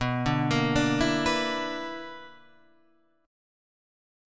B Minor Major 7th Double Flat 5th